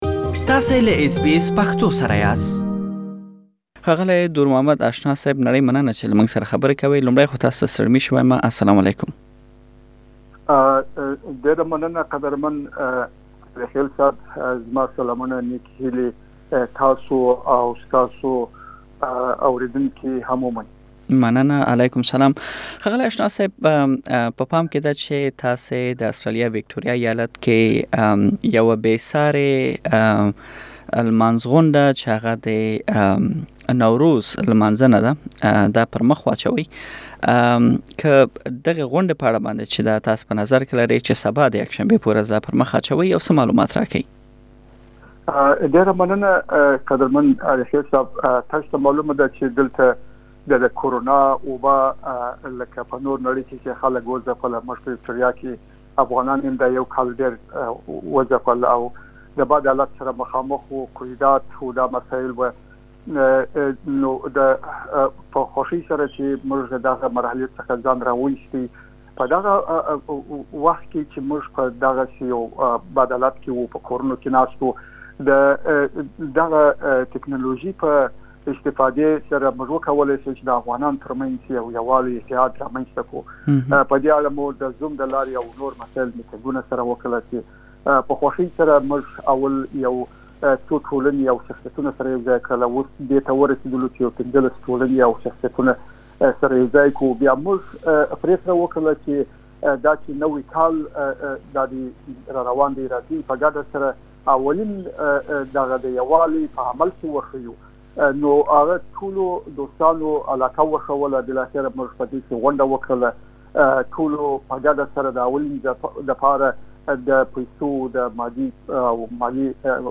مرکه کړې چې تاسې بشپړه مرکه دلته اوريدلی شئ.